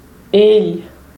gl /ʎ/